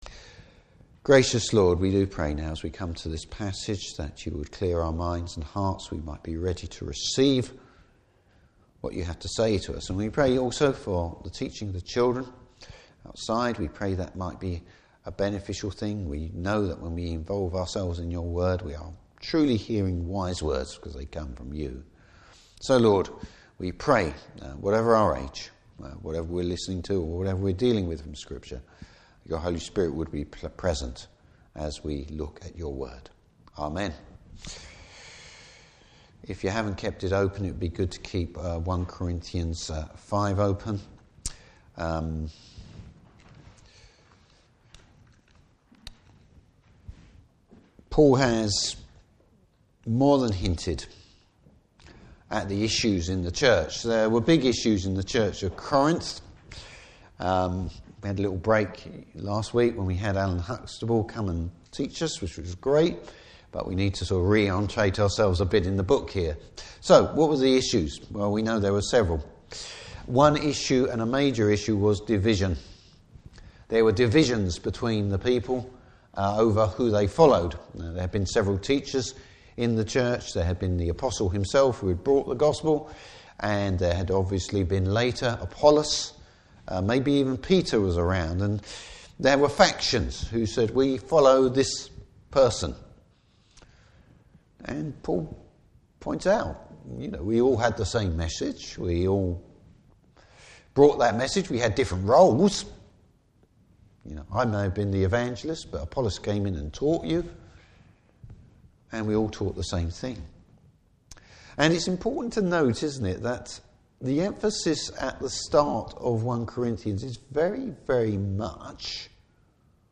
Service Type: Morning Service Paul deals with the Church’s failure to discipline someone guilty of sexual immorality.